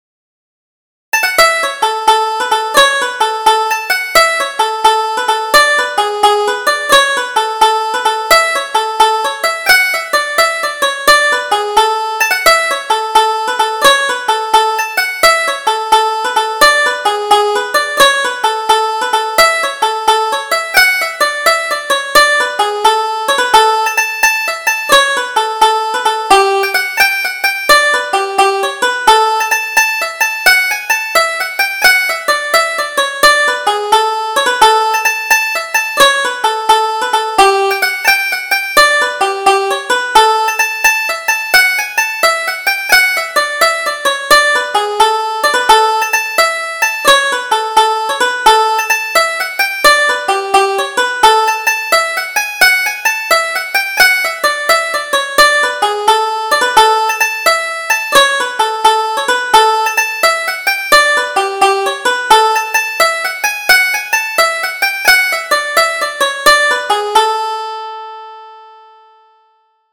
Double Jig: The Rivals